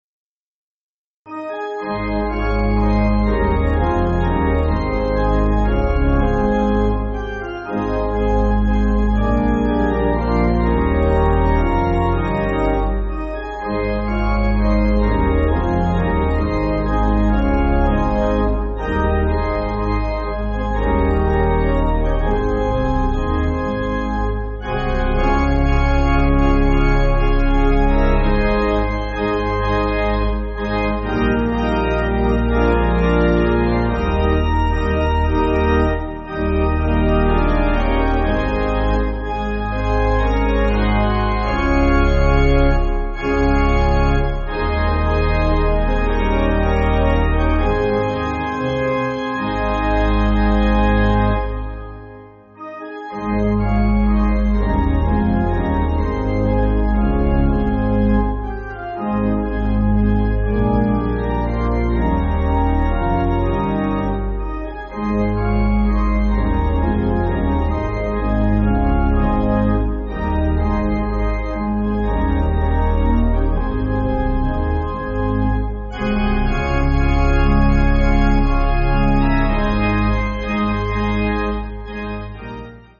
Organ
(CM)   4/Ab